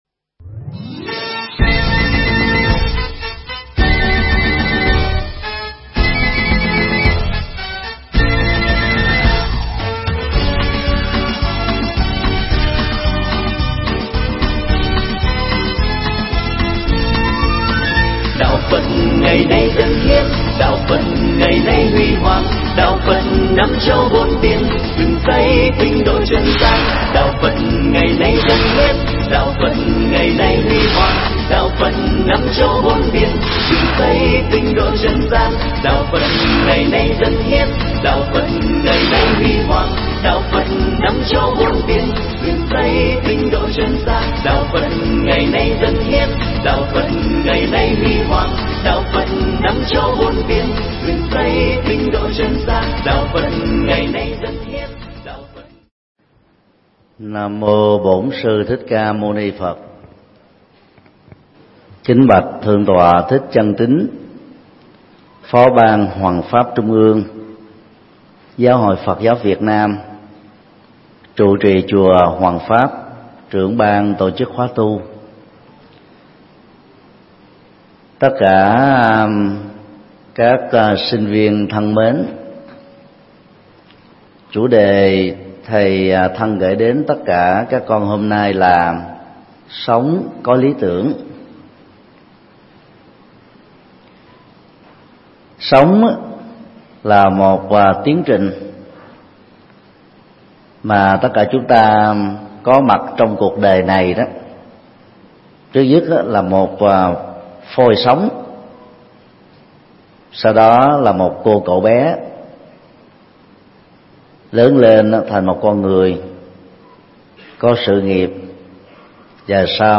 Mp3 Thuyết giảng Sống có lý tưởng
giảng tại chùa Hoàng Pháp, Hóc Môn,TP Hồ Chí Minh